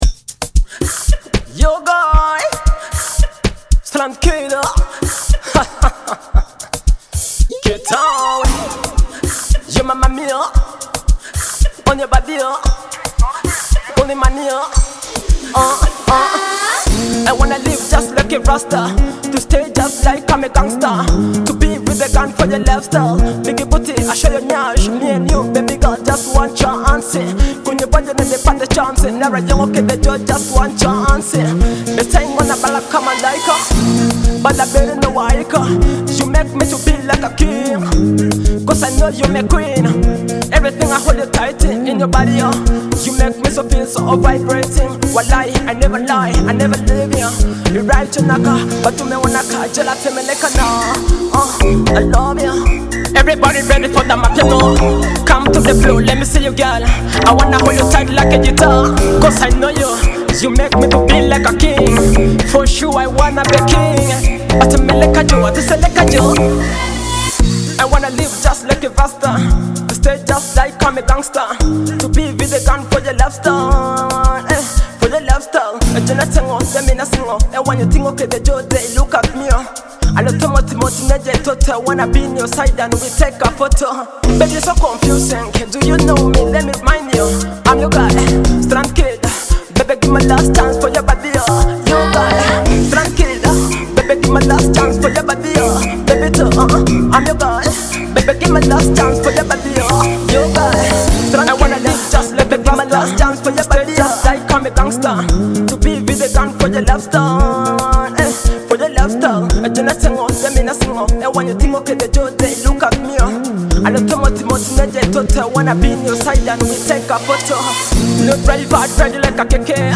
soulful melodies